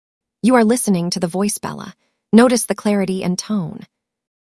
- High-Quality Audio: Delivers clear and expressive voices with a natural tone.
Sample Audio Voices: